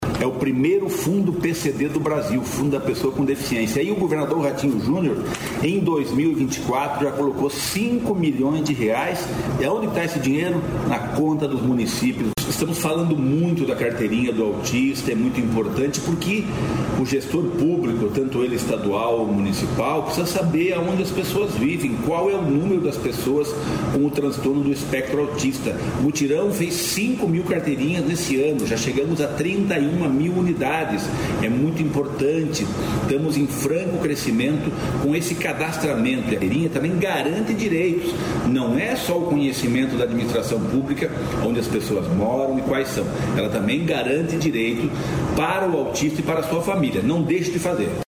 Sonora do secretário do Desenvolvimento Social e Família, Rogério Carboni, sobre direitos da pessoa com deficiência